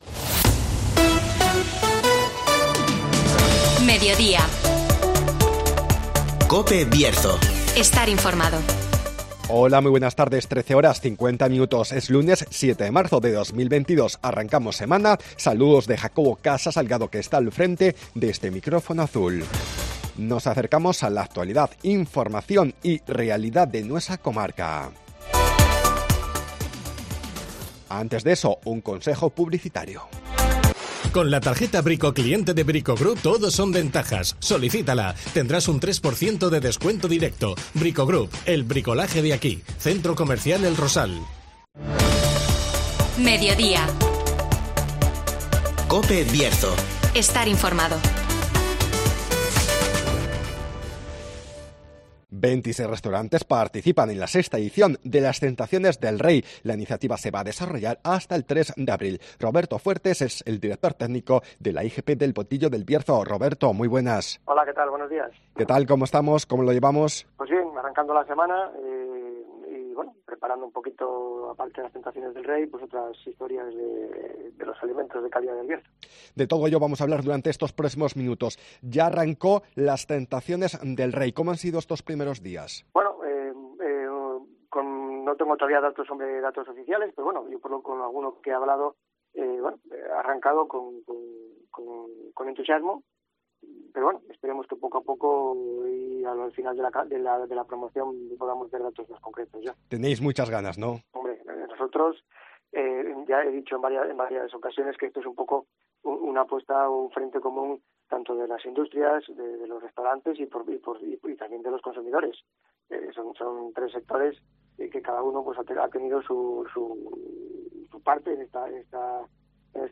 26 restaurantes participan en la sexta edición de 'Las tentaciones del Rey (Entrevista